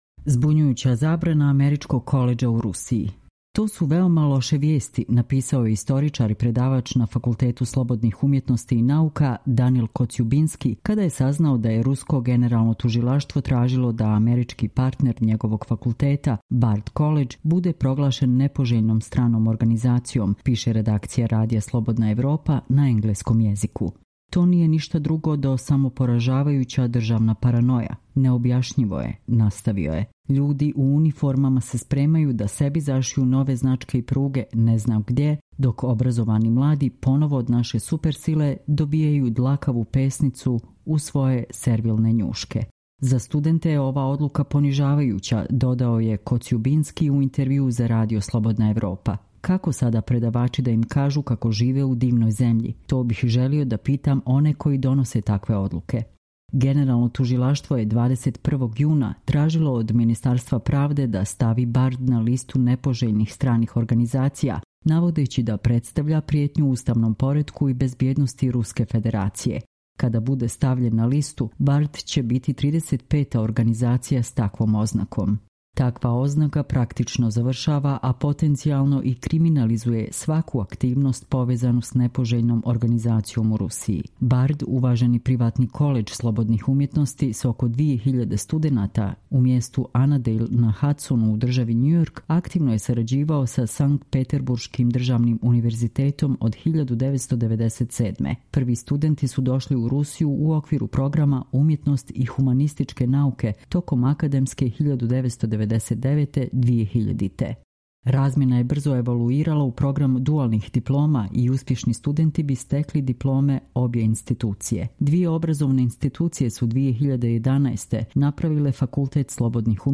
Čitamo vam: Zbunjujuća zabrana američkog koledža u Rusiji